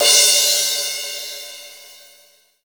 • Crash Single Shot E Key 06.wav
Royality free crash tuned to the E note. Loudest frequency: 5881Hz
crash-single-shot-e-key-06-50p.wav